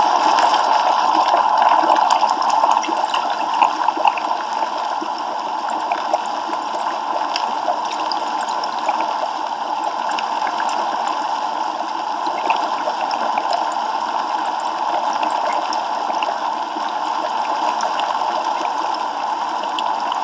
Boil
Categories: Cooking, Water
Water-Boiling.m4a